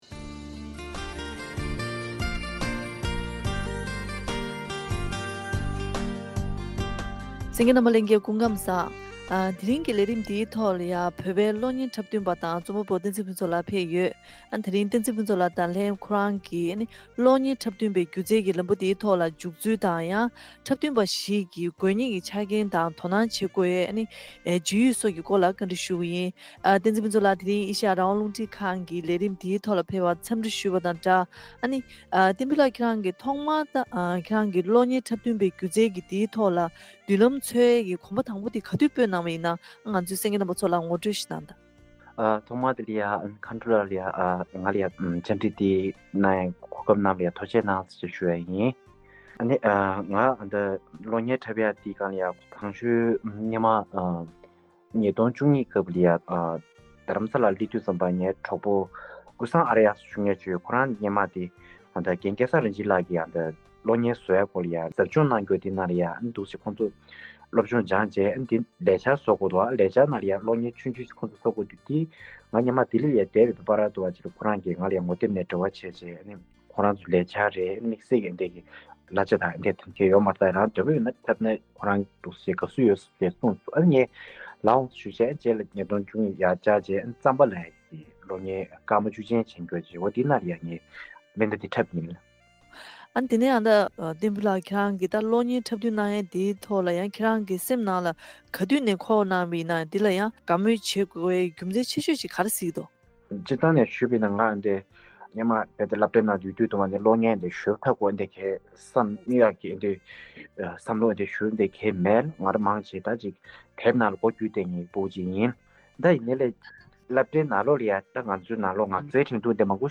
བཀའ་འདྲི་ཞུས་པའི་ལས་རིམ་ཞིག་གསན་གནང་གི་རེད།